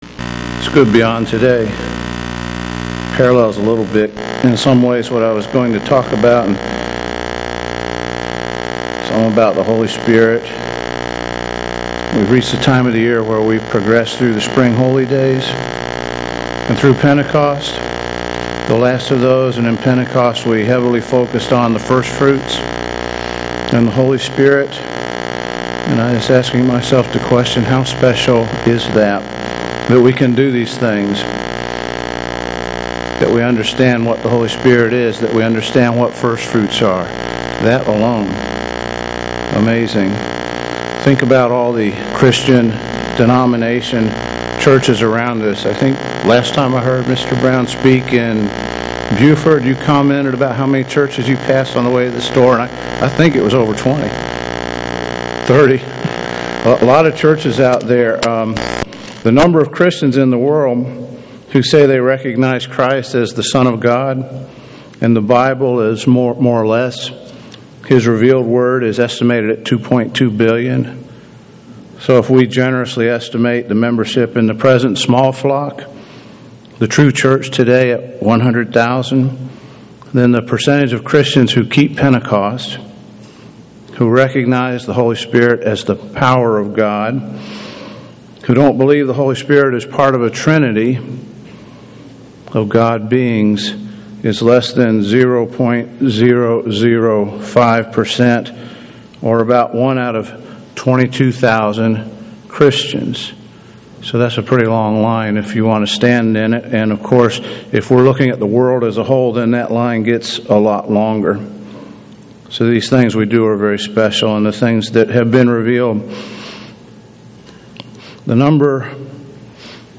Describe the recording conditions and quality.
[Note - a buzz is audible at the beginning of this message but will be eliminated about a minute into the sermon.] Given in Atlanta, GA